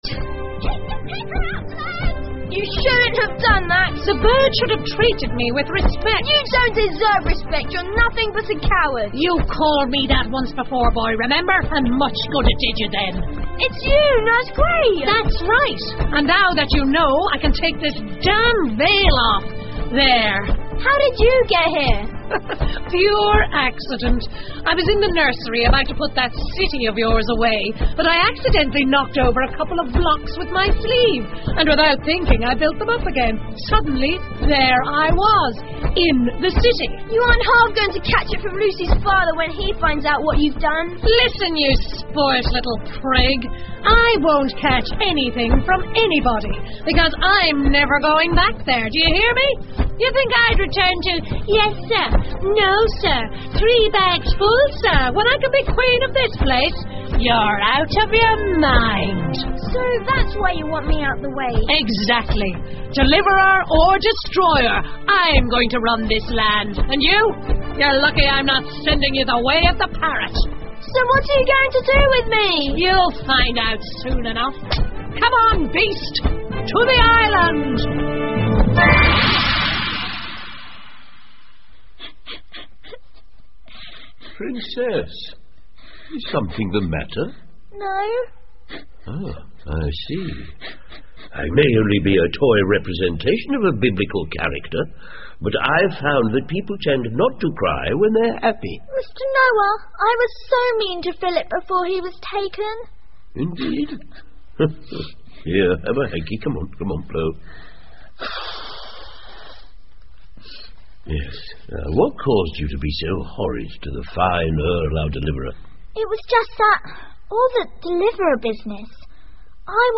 魔法之城 The Magic City by E Nesbit 儿童广播剧 18 听力文件下载—在线英语听力室